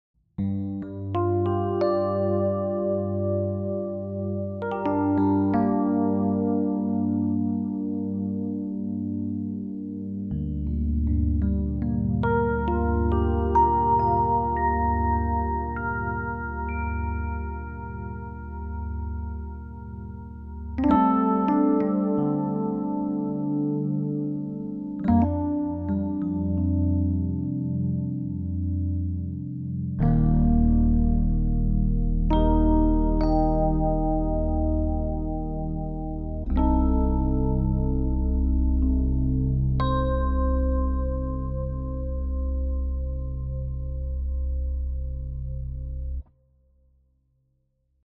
Rhodes – LA Custom Lush